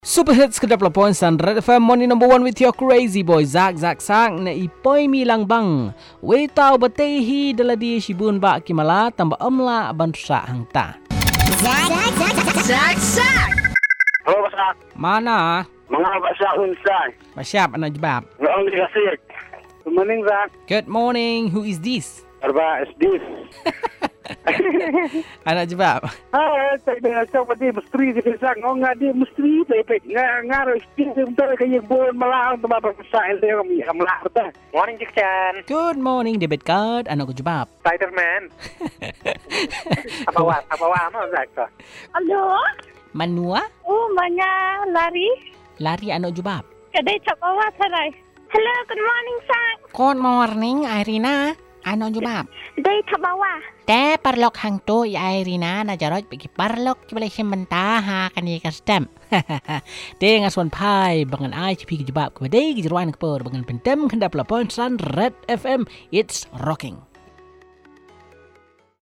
Calls